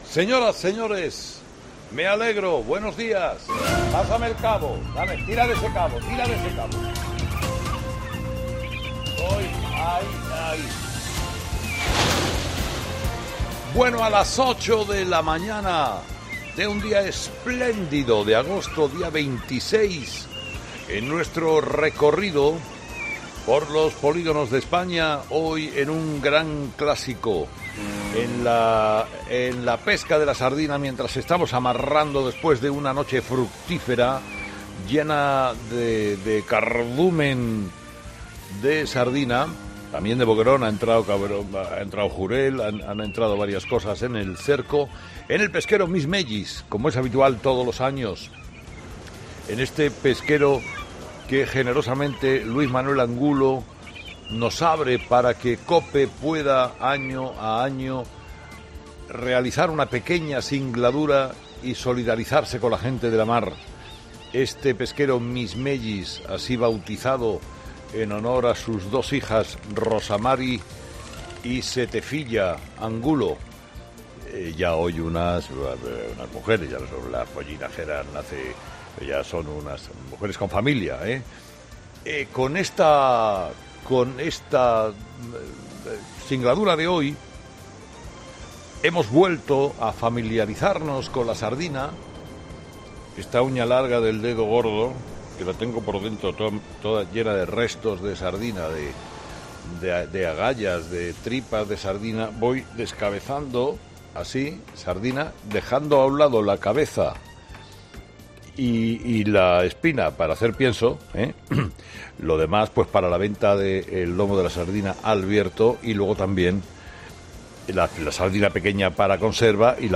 ESCUCHA EL EDITORIAL COMPLETO DE CARLOS HERRERA